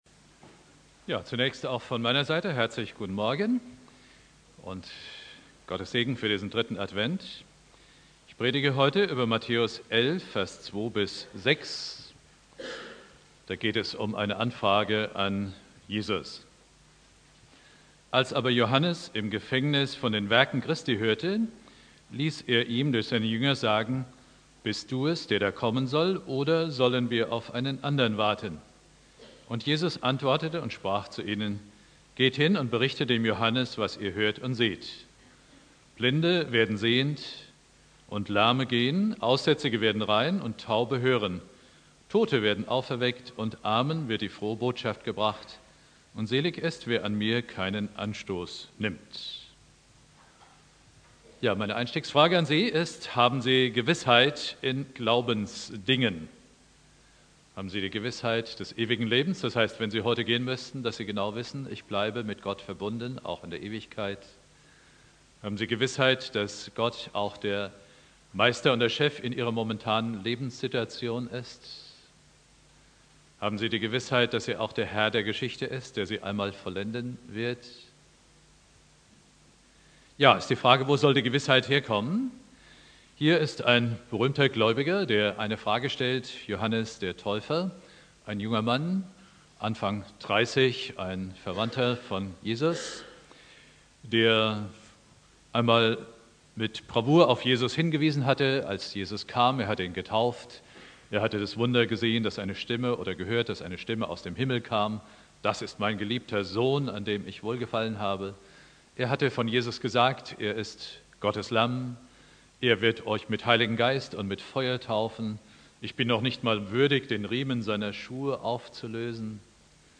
Predigt
3.Advent